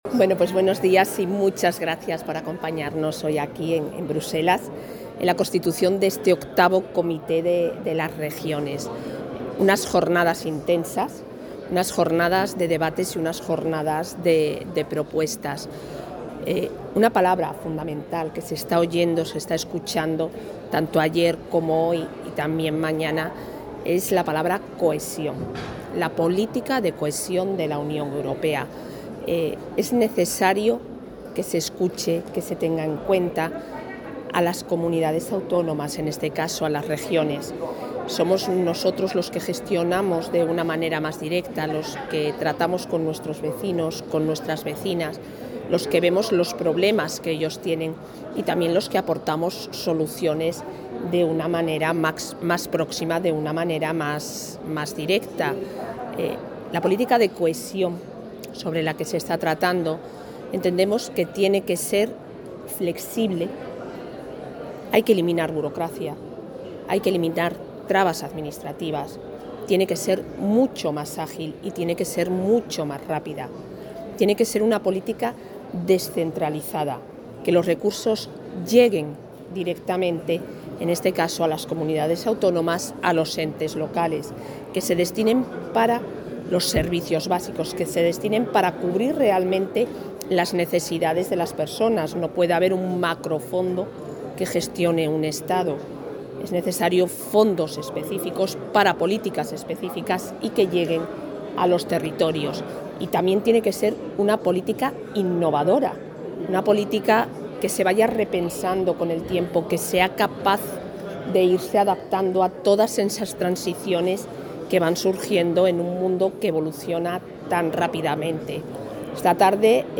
La vicepresidenta de la Junta de Castilla y León, Isabel Blanco, interviene hoy en el pleno del Comité de las Regiones en dos debates...
Declaraciones de la vicepresidenta de la Junta.